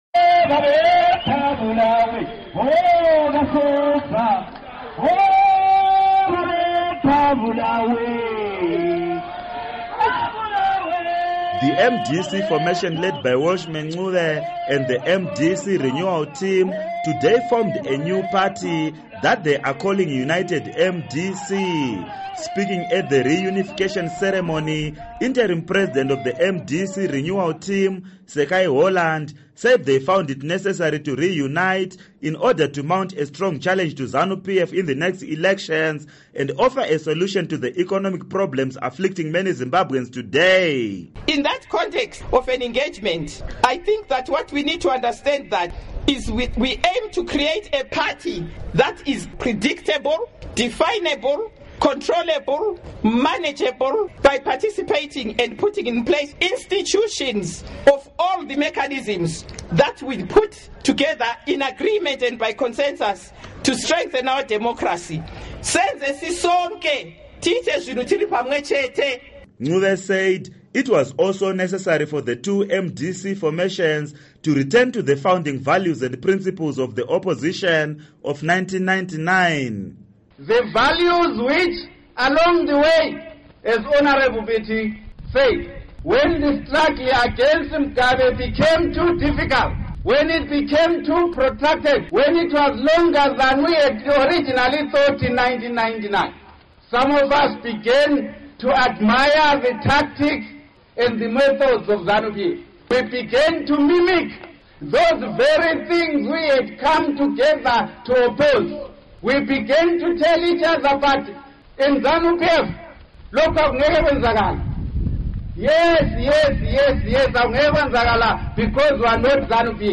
Report on MDC Unity Agreement